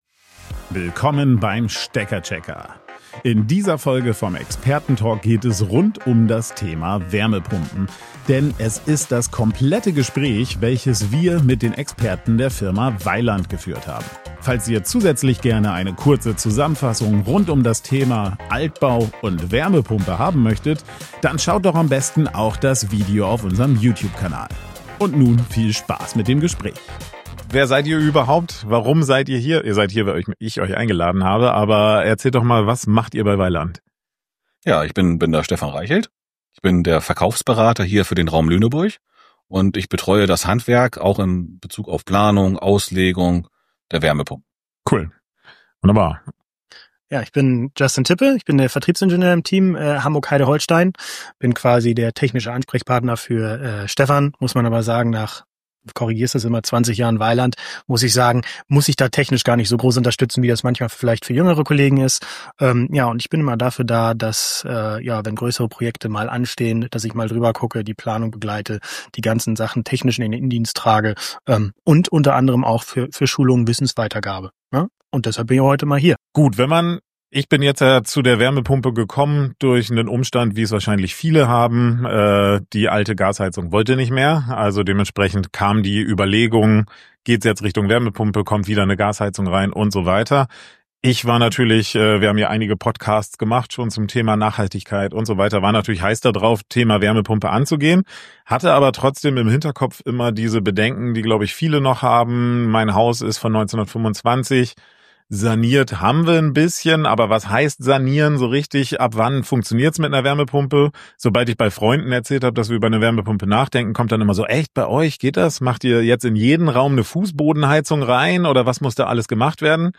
Dies ist das vollständig aufgezeichnete Gespräch, das eine Vielzahl an aktuellen Fragen und Mythen rund um den Einsatz von Wärmepumpen klärt, insbesondere in Altbauten.